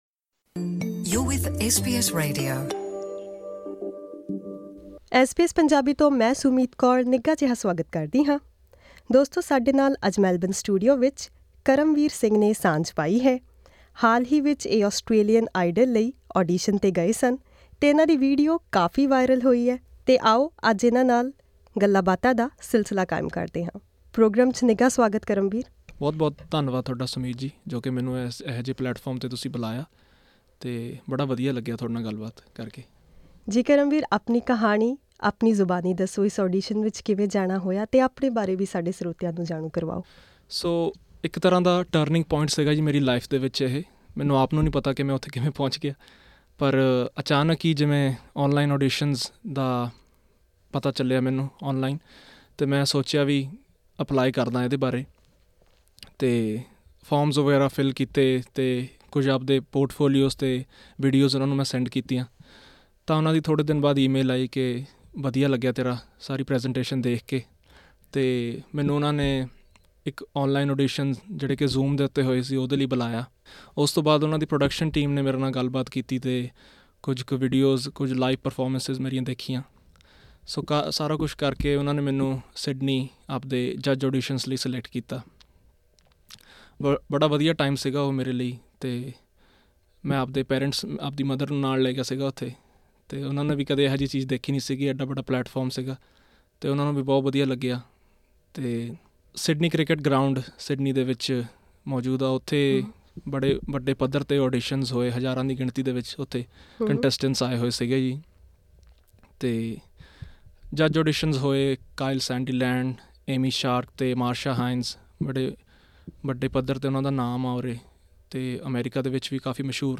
SBS Studios, Melbourne.